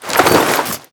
tac_gear_2.ogg